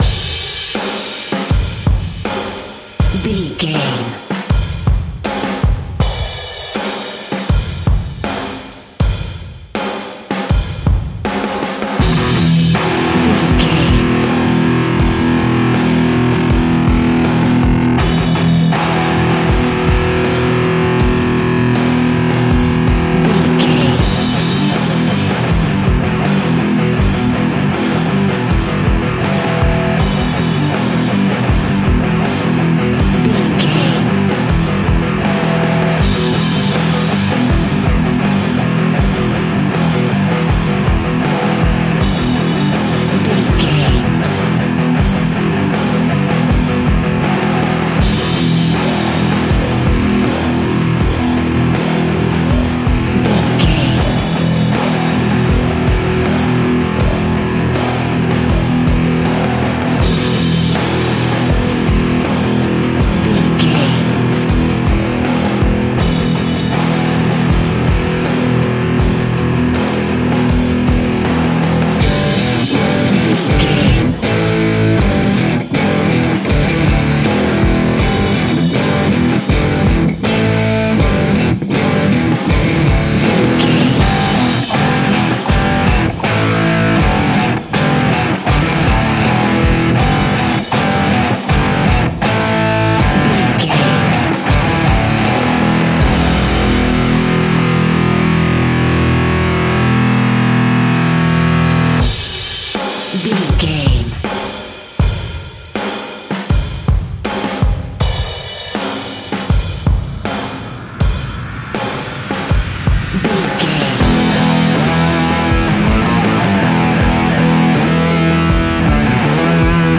Ionian/Major
hard rock
heavy metal
distortion